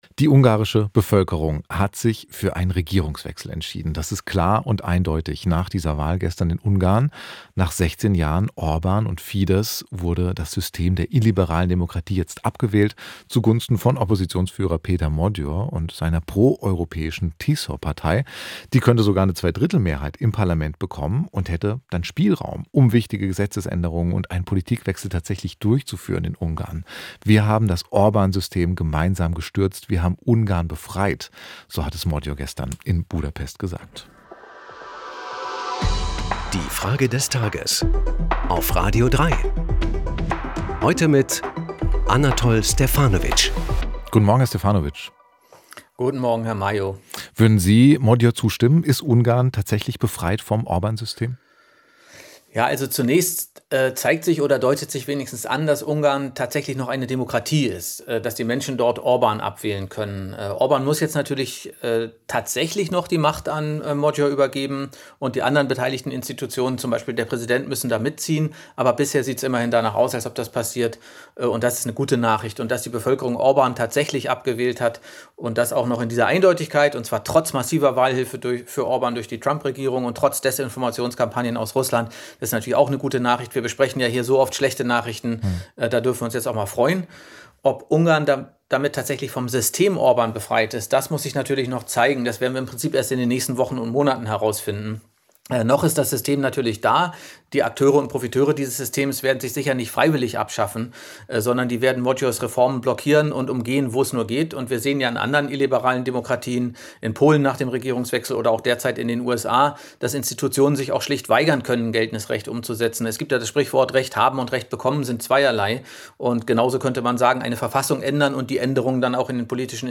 Wir fragen unseren Kommentator, den Sprachwissenschaflter und